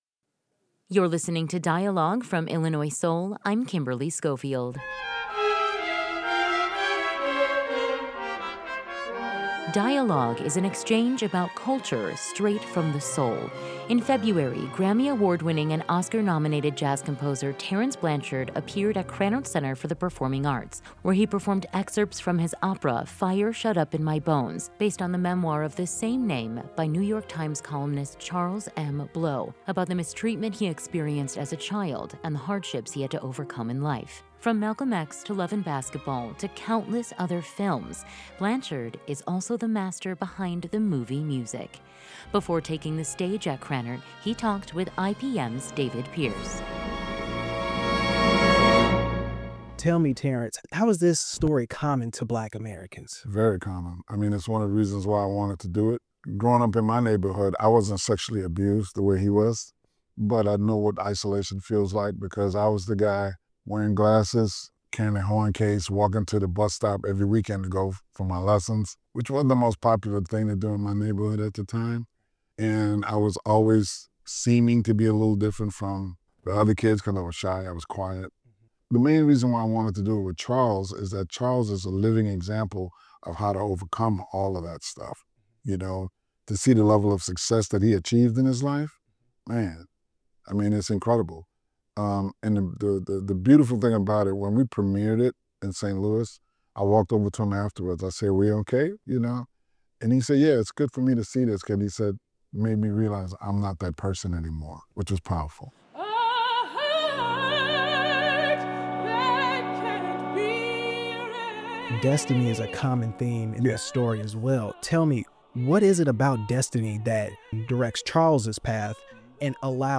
__ GUESTS: Terence Blanchard American Jazz trumpeter and composer Tags terence blanchard jazz fire shut up in my bones opera